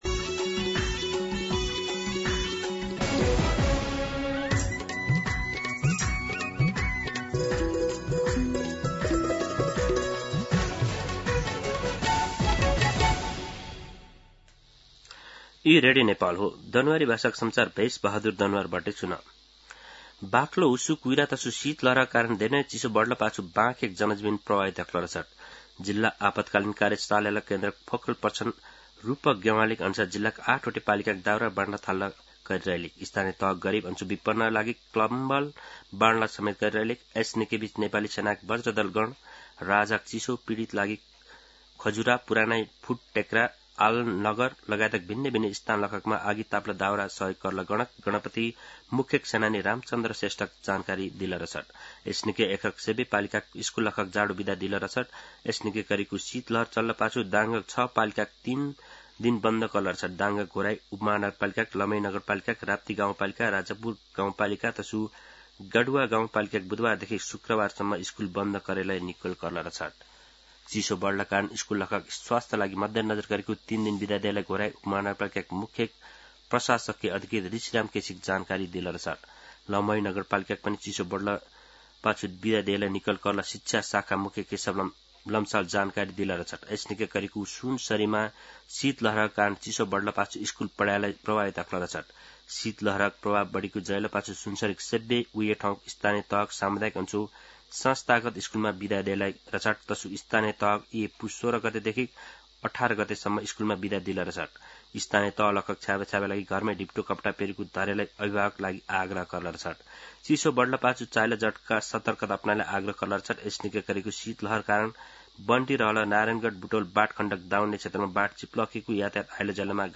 दनुवार भाषामा समाचार : १६ पुष , २०८२
Danuwar-news-9-16.mp3